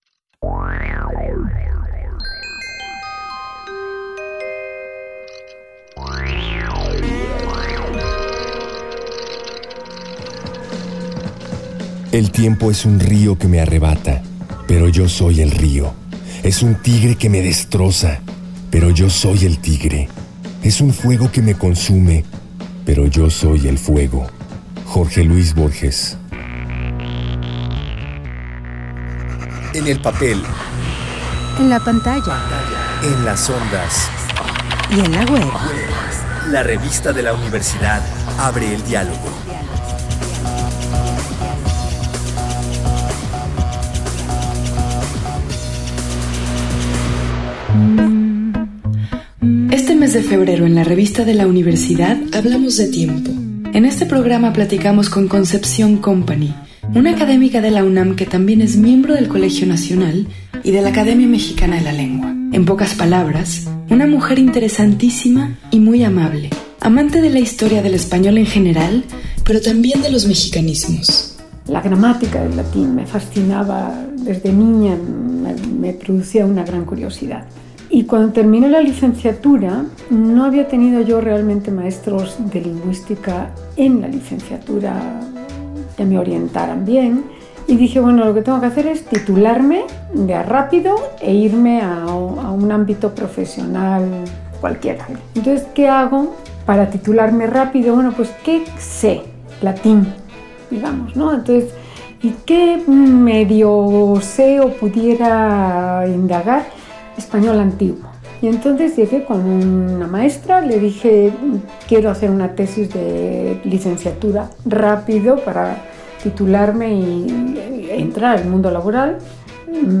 Cargar audio Este programa es una coproducción de la Revista de la Universidad de México y Radio UNAM. Fue transmitido el jueves 1 de marzo de 2018 por el 96.1 FM.